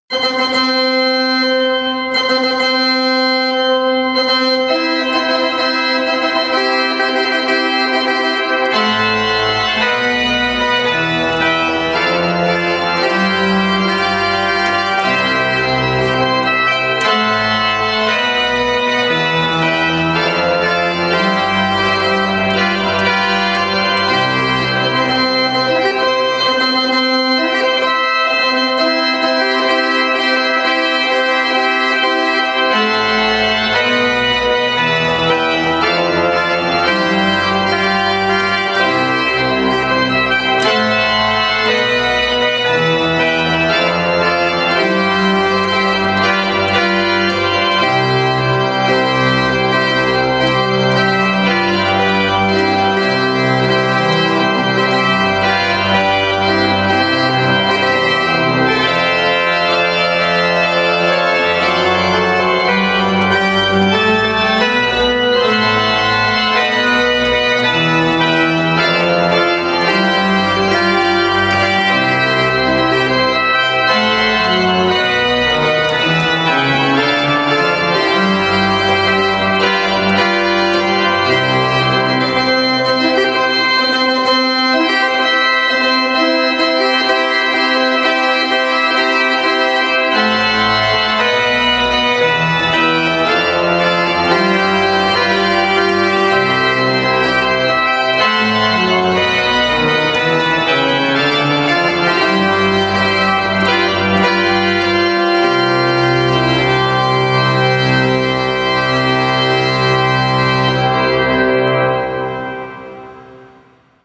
Flauto e Chitarra